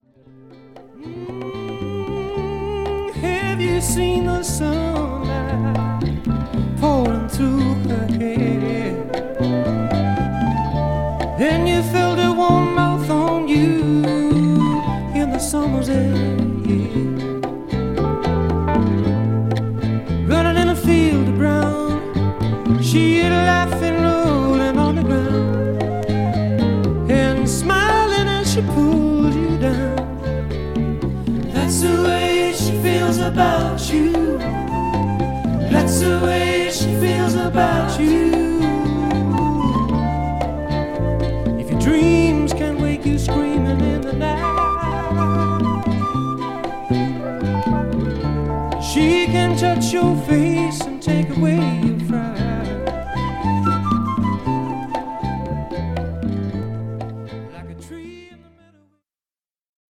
ライブ・アルバム。